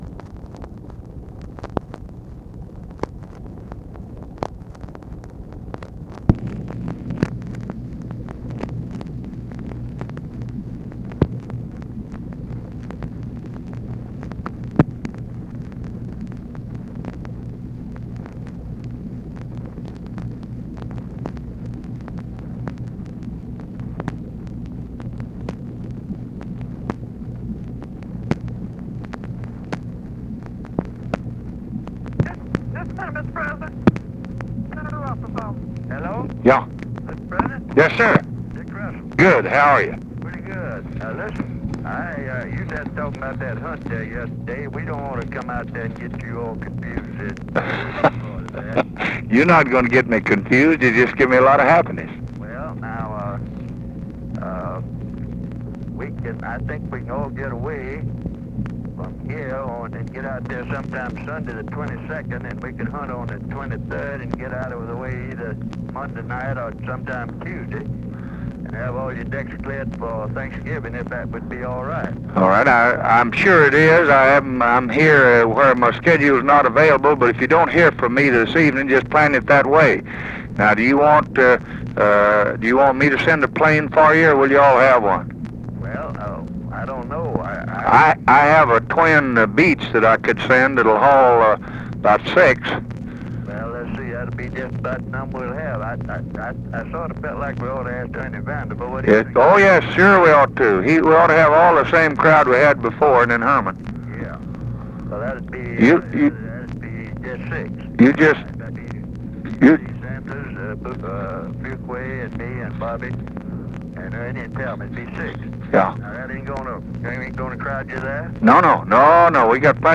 Conversation with RICHARD RUSSELL, November 10, 1964
Secret White House Tapes